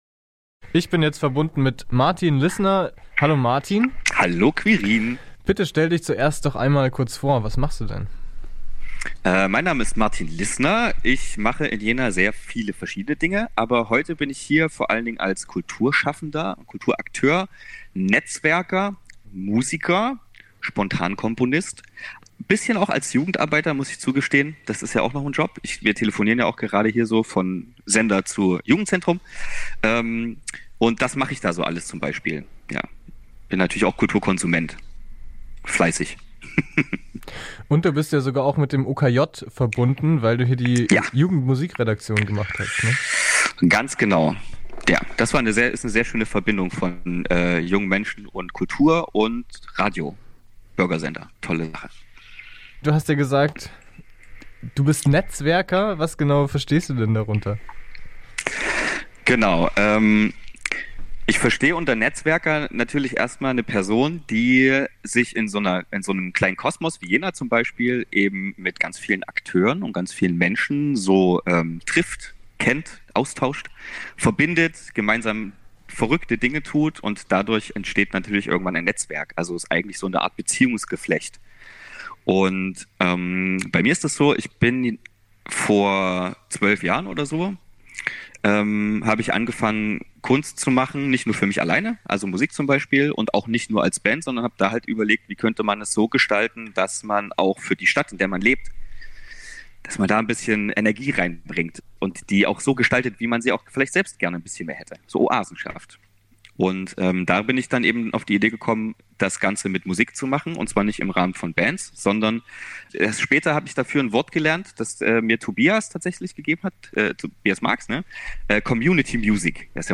In der Sondersendung Soziokultur kommen Akteur*innen der Jenaer Szene zu Wort und berichten von Ihrer Situation in der Pandemie. Im Interview sprechen Sie über ihre psychische Verfassung, Hygienekonzepte, Corona-Nothilfen, und die Wirkung der Einschränkungen auf die Jenaer Szene.